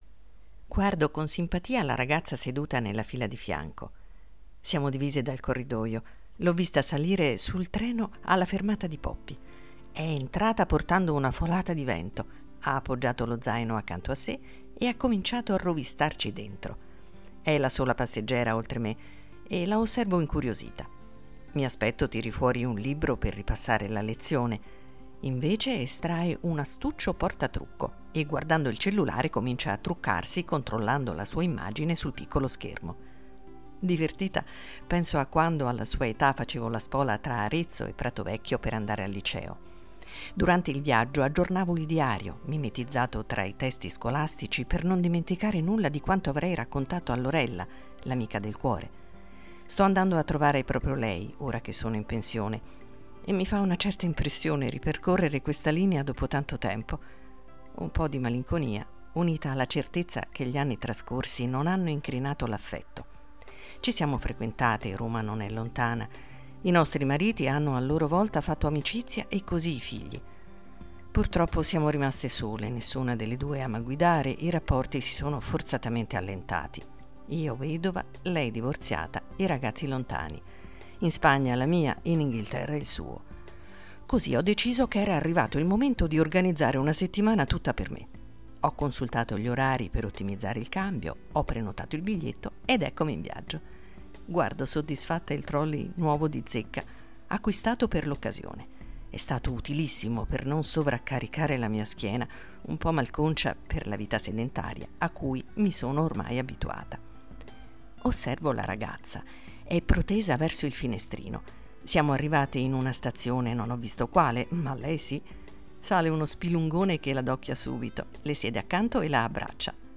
Music under courtesy of Forte Media & Consulting Sagl